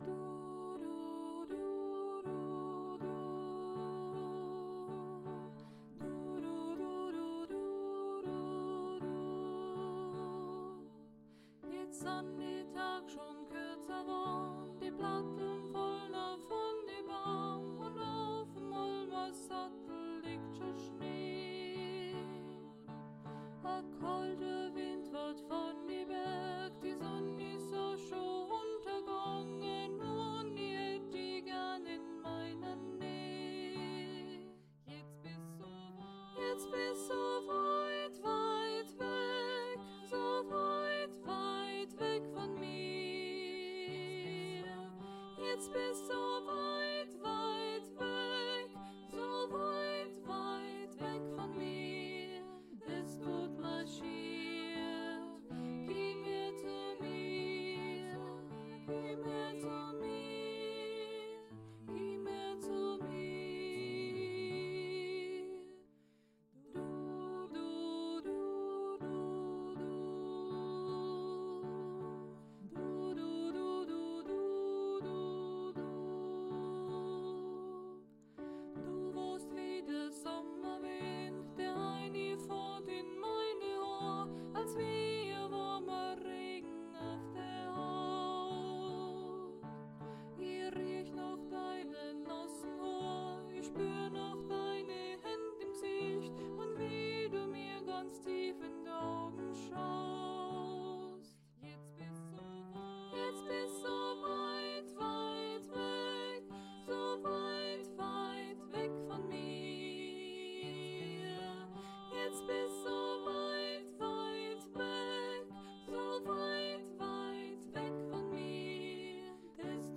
aweitwegsopran.mp3